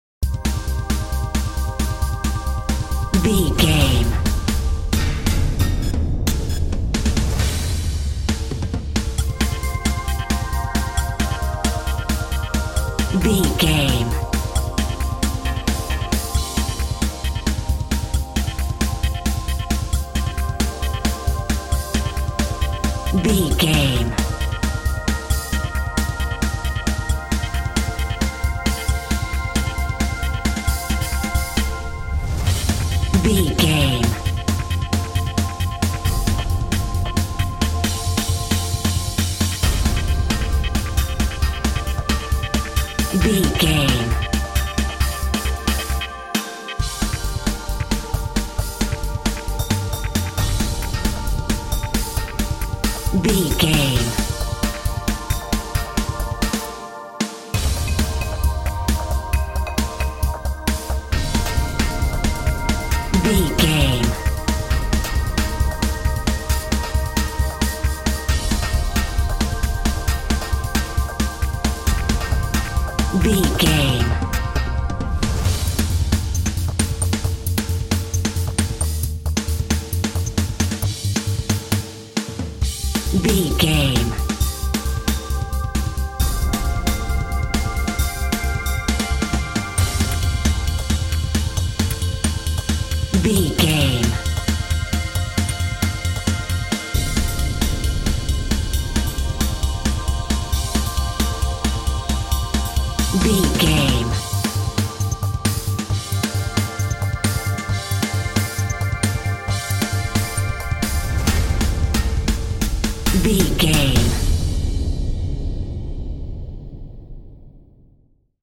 Aeolian/Minor
Fast
intense
8bit
aggressive
dramatic
energetic
synth
drums
percussion
ominous